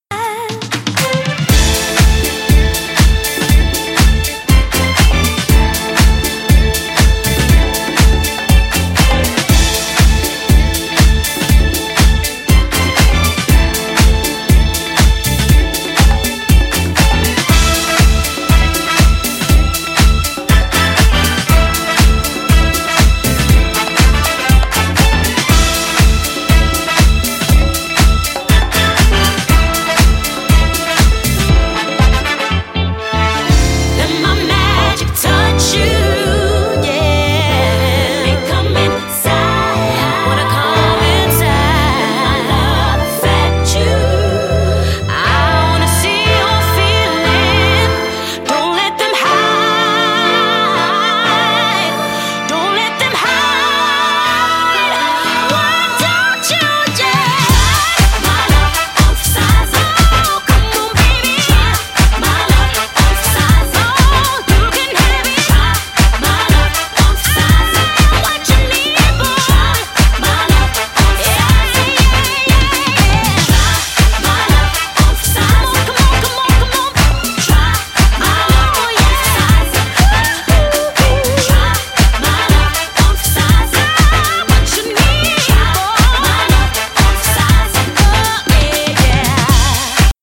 ジャンル(スタイル) DISCO / GARAGE / NU DISCO / SOULFUL HOUSE